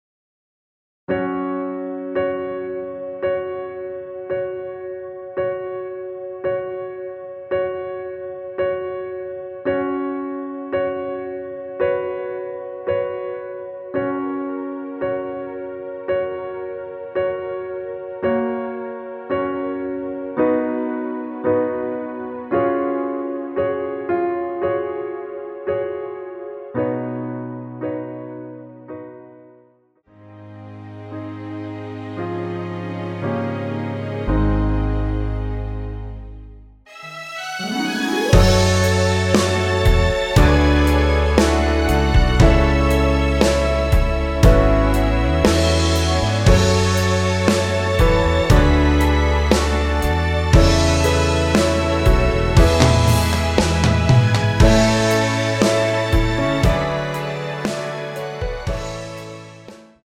원키에서(+3)올린 MR입니다.
F#
앞부분30초, 뒷부분30초씩 편집해서 올려 드리고 있습니다.
중간에 음이 끈어지고 다시 나오는 이유는